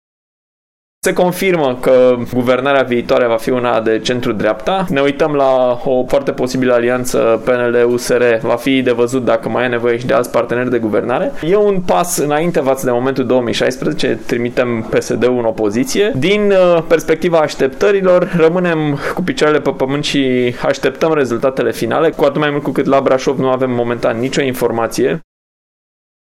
Primarul Braşovului, Allen Coliban: